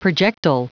Prononciation du mot projectile en anglais (fichier audio)
projectile.wav